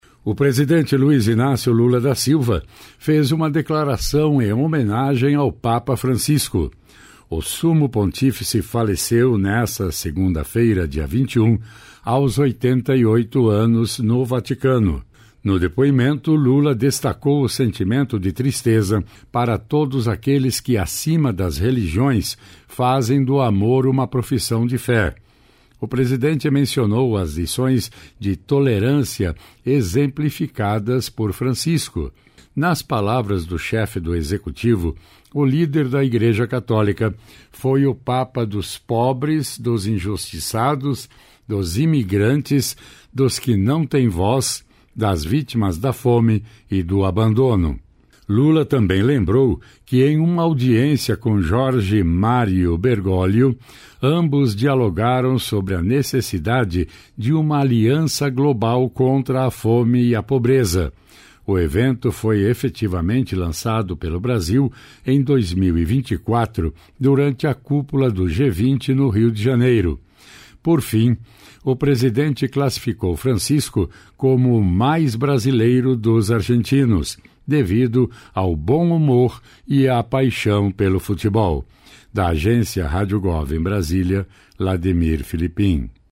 O presidente Luiz Inácio Lula da Silva fez uma declaração em homenagem ao papa Francisco.